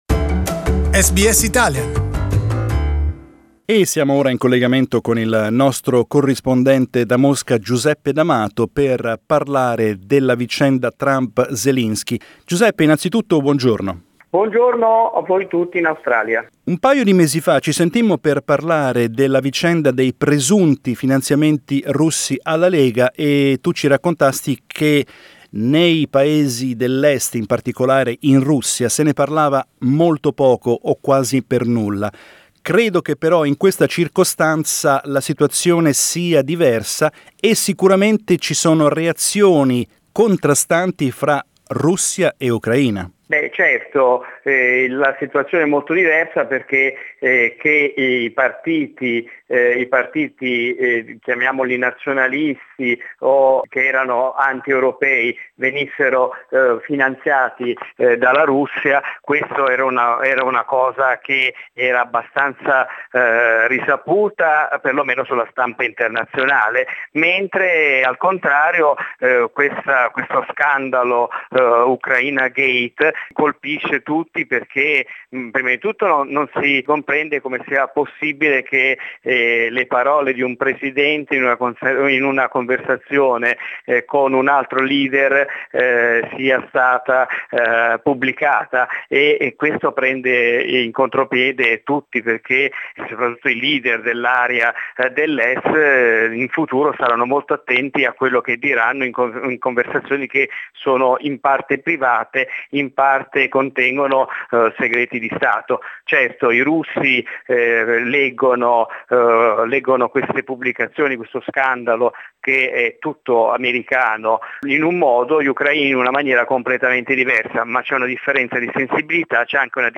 Our Moscow correspondent